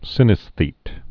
(sĭnĭs-thēt)